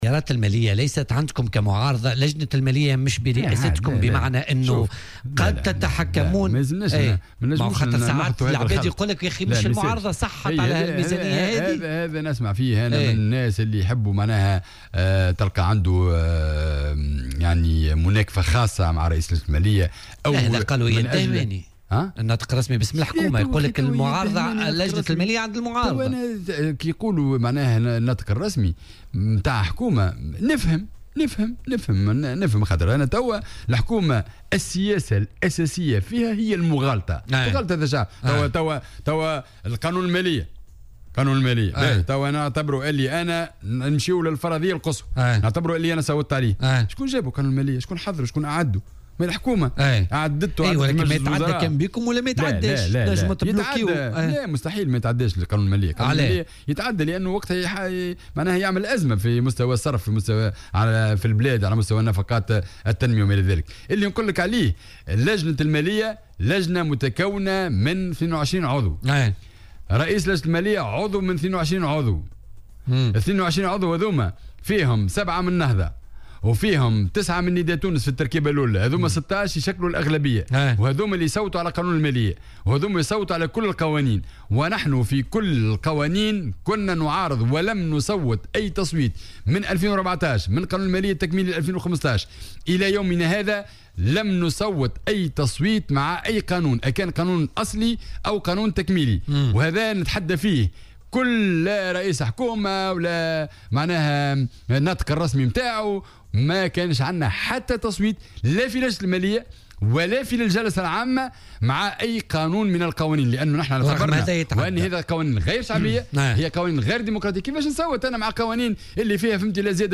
وأضاف في مداخلة له اليوم في برنامج "بوليتيكا" على "الجوهرة أف أم" أن هذه القوانين "غير شعبية" وغير "ديمقراطية"، منتقدا سياسات الحكومة التي تهدف إلى " جلد الشعب ونهش قوت المواطنين"، وفق تعبيره.